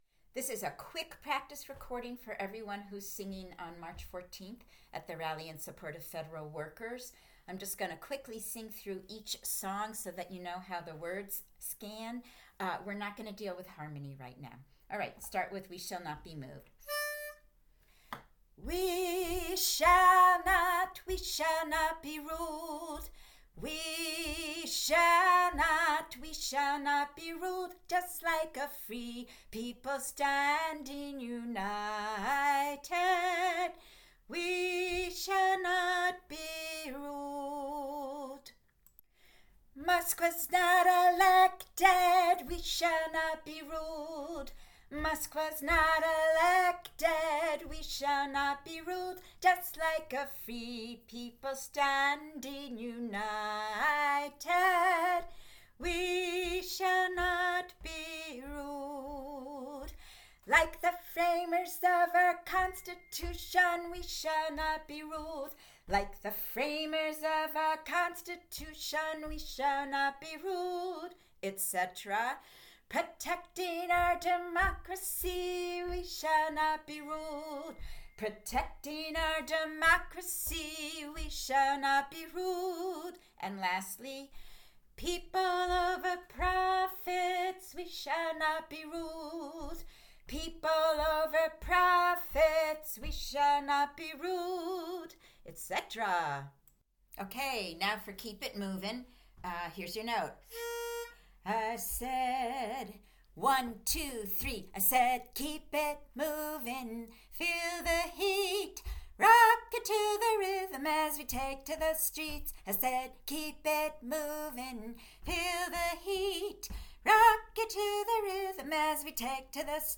March14 Rally for Federal workers
at JFK building, Boston, Fri 3/14/25 6:00PM
March 14 practice.mp3